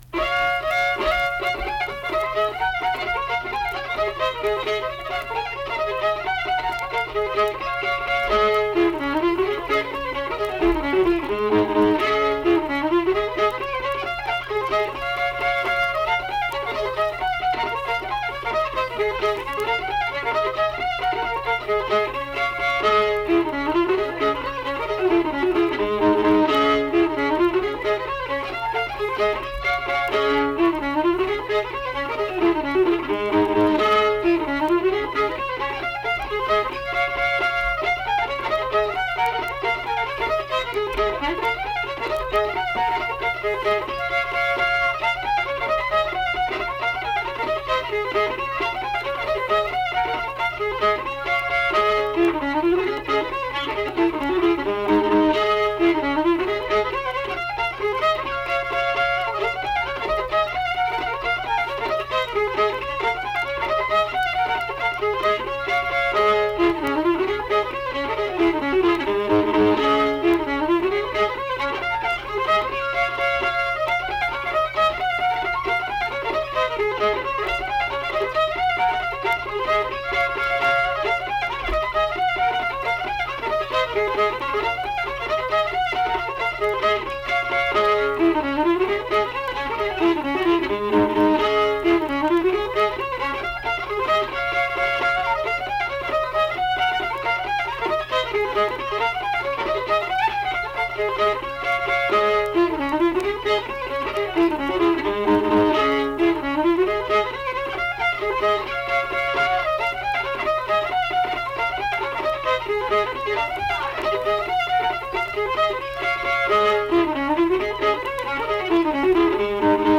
Unaccompanied fiddle music
Instrumental Music
Fiddle
Flatwoods (Braxton County, W. Va.), Braxton County (W. Va.)